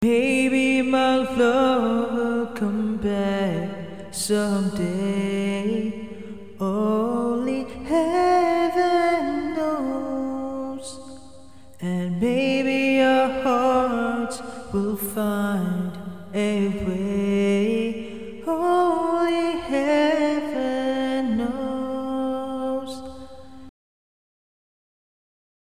I recorded a few short audio samples to demonstrate how the Maonocaster Lite AU-AM200-S1 handles recording. Please excuse my singing voice.
Mic only with 100% echo(onboard) – vocal
Mic-only-with-100-echoonboard-vocal.mp3